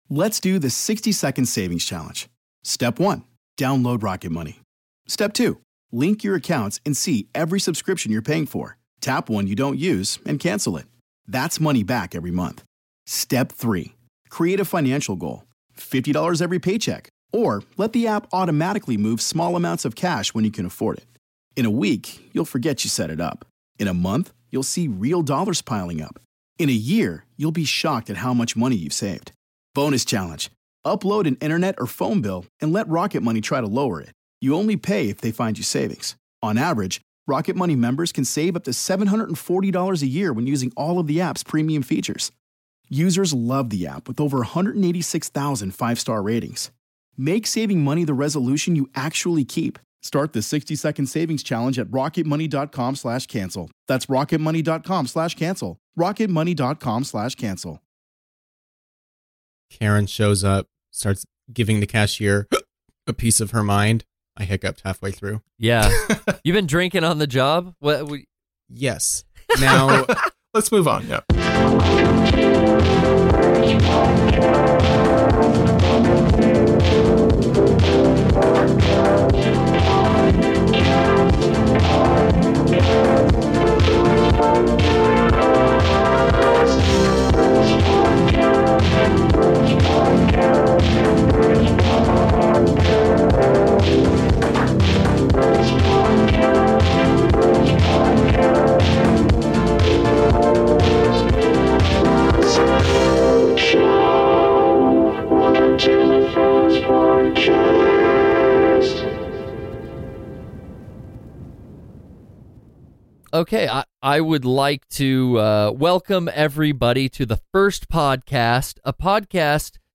This episode was recorded live on our Discord.